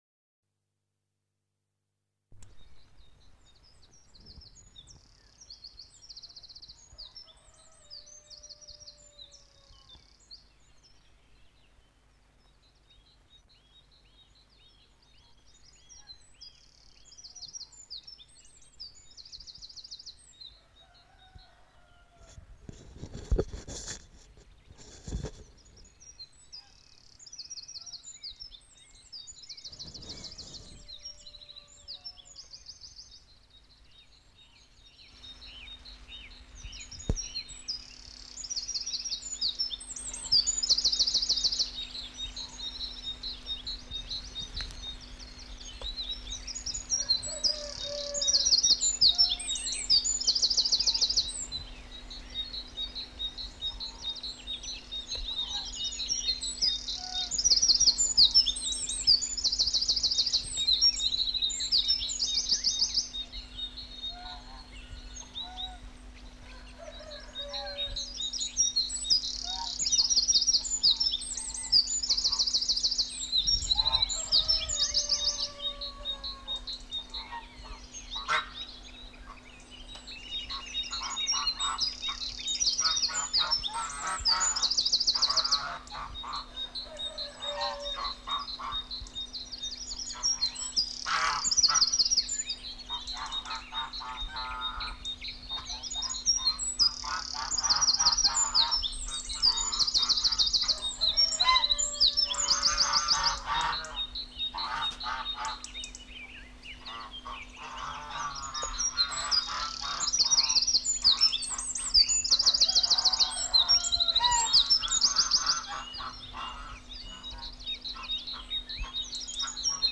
Chants d'oiseaux (oies, mésanges, grives)
Aire culturelle : Savès
Lieu : Gers
Genre : paysage sonore